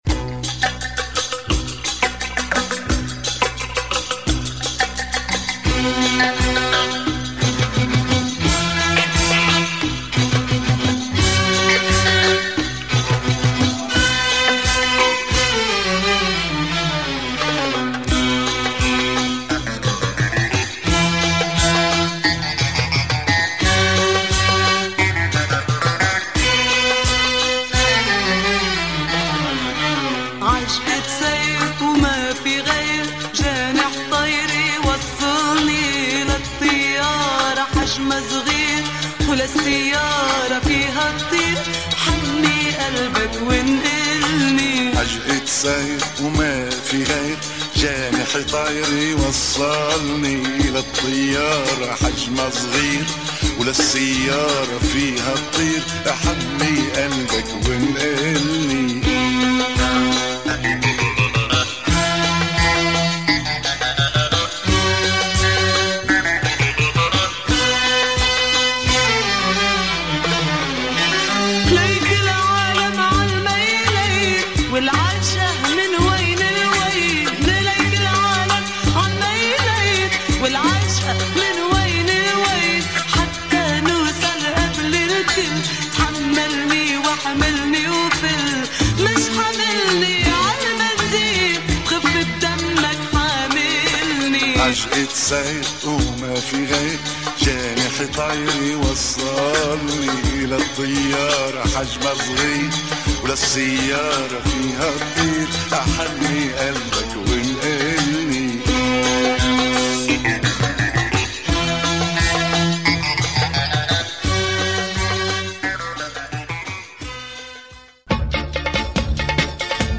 Kinda roboticized beats